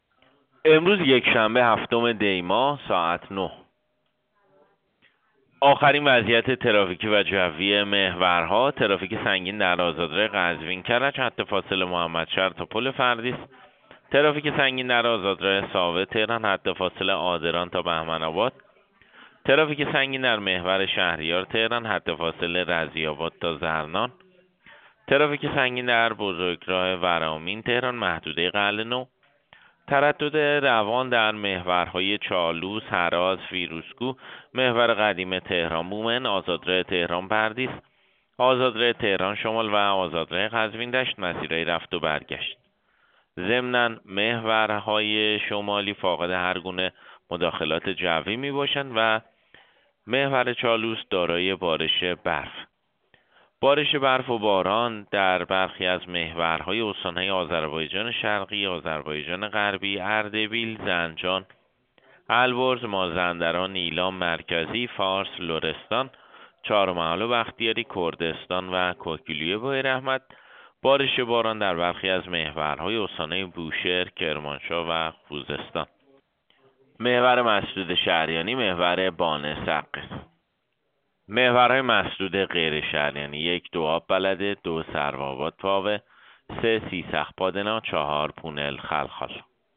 گزارش رادیو اینترنتی از آخرین وضعیت ترافیکی جاده‌ها ساعت ۹ هفتم دی؛